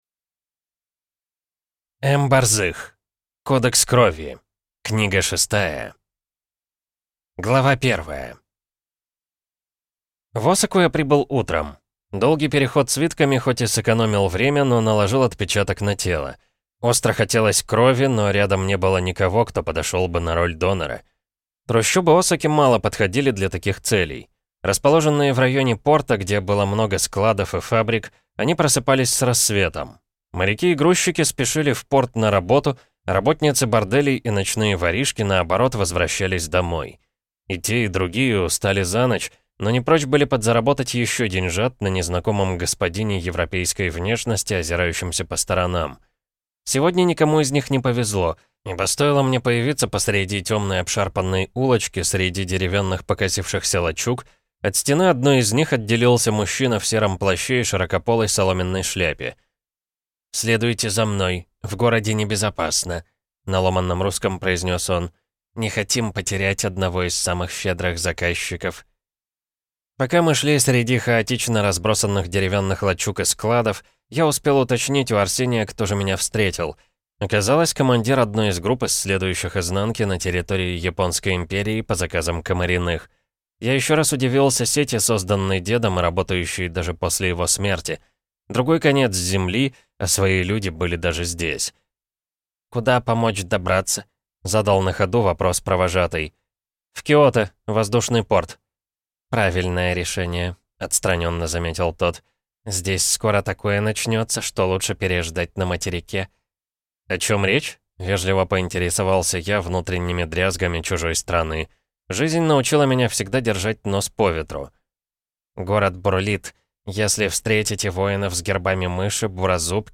Добро не оставляйте на потом (слушать аудиокнигу бесплатно) - автор Адриана Трижиани